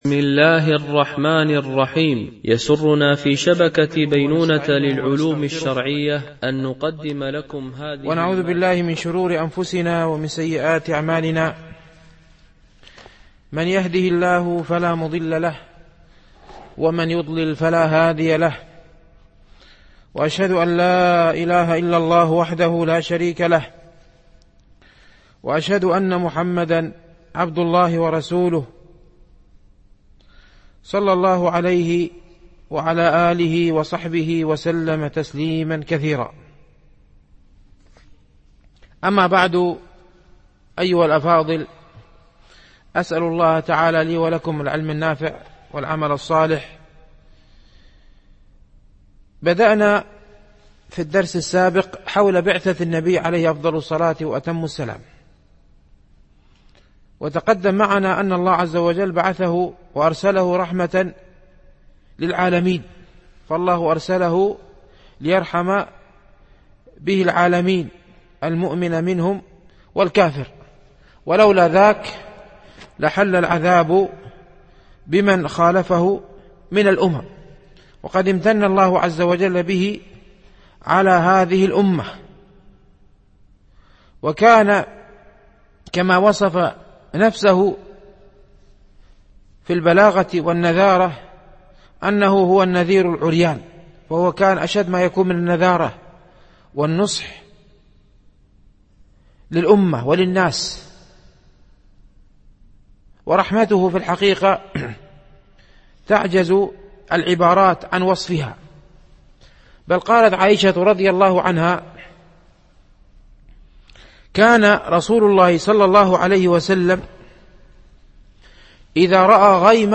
مهمات في السيرة ـ الدرس السادس